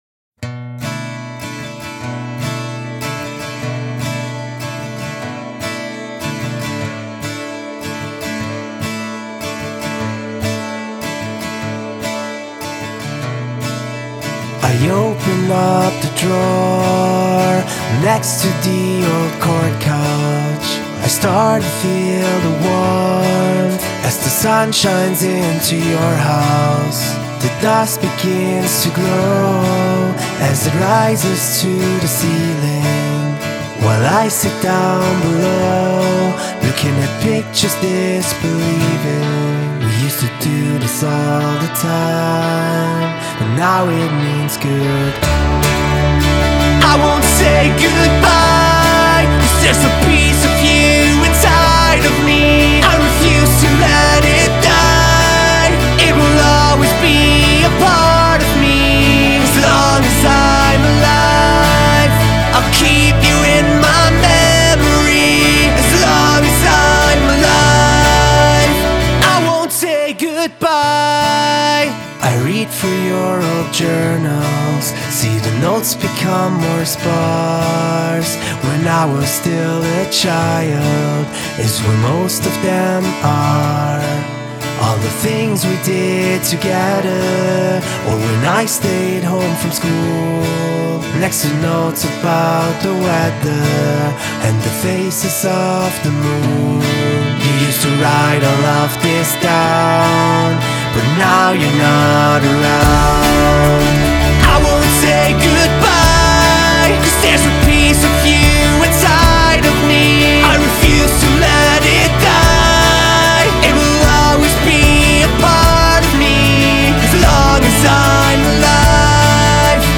Die emotionale und musikalisch teils minimalistische Ballade